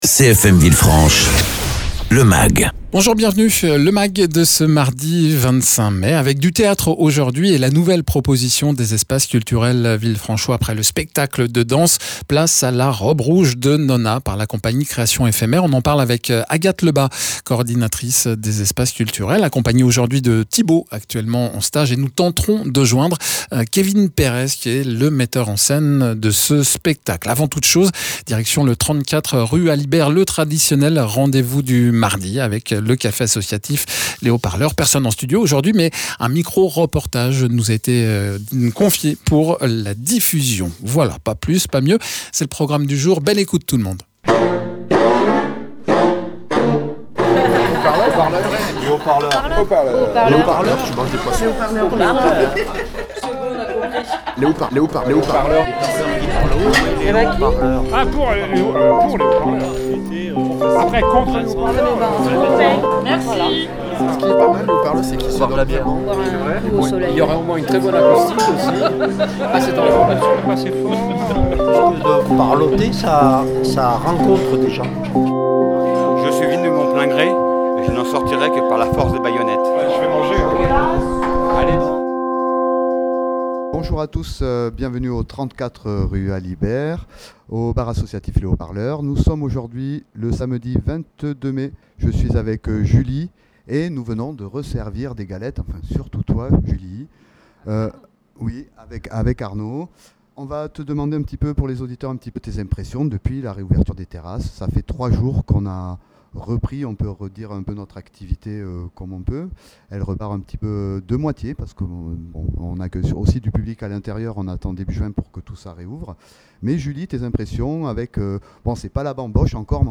Le programme des animations de la semaine au café associatif en mode micro reportage, le menu du jeudi, réouverture de la terrasse, le pique-nique des bénévoles et un appel à propositions d’animation dans le cadre de la réouverture. Egalement dans ce mag, présentation du spectacle « la robe rouge de nonna » joué pour le jeune public au théâtre municipal.